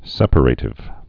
(sĕpə-rātĭv, sĕpər-ə-, sĕprə-)